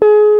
JAZZGUITAR 3.wav